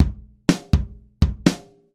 ここでは "DSK DrumZ" というVSTiでシンプルなリズムを打ち込んでみました。
DSK DrumZで打ち込んだ音をRenderしたフレーズ・サンプル（MP3）